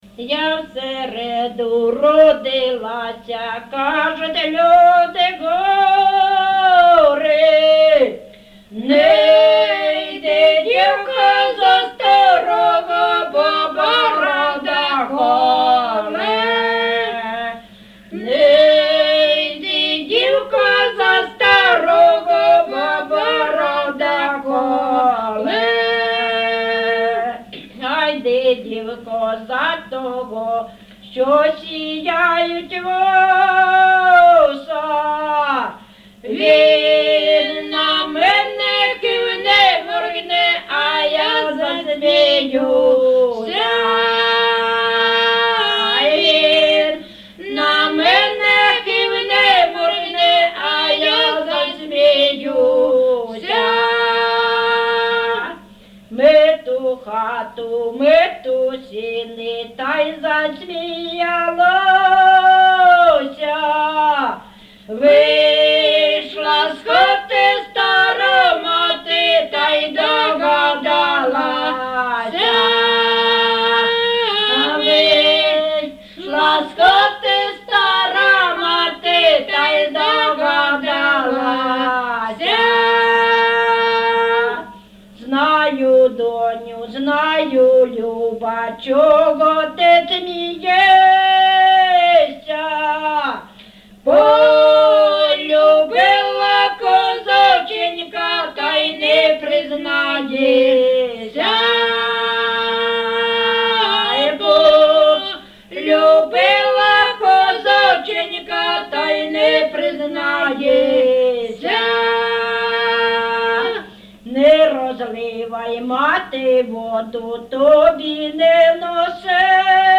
ЖанрПісні з особистого та родинного життя, Жартівливі
Місце записум. Старобільськ, Старобільський район, Луганська обл., Україна, Слобожанщина